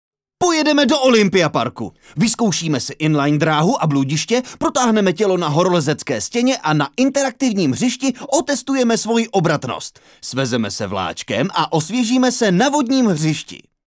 reklama.mp3